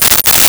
Coins Shake In Hand 01
Coins Shake in Hand 01.wav